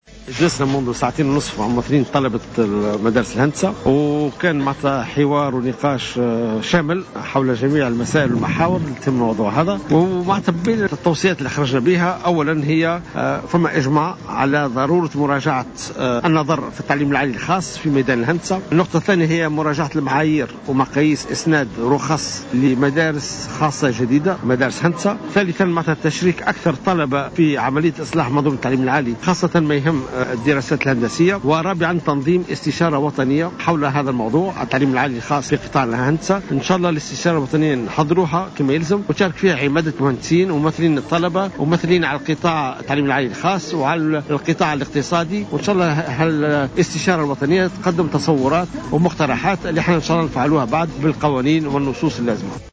Taoufik Jelassi, ministre de l’enseignement supérieur et de la recherche scientifique, a déclaré ce lundi 12 janvier 2015 au micro de Jawhara FM, que l’enseignement supérieur privé en ingénierie sera révisé.